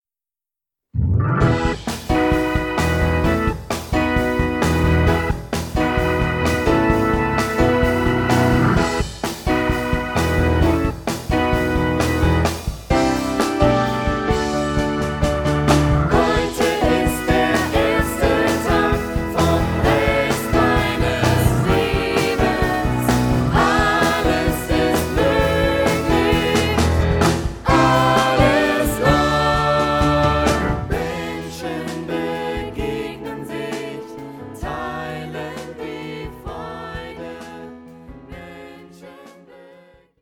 Gesang und Schlagzeug
Gesang und Klavier
Hammond Organ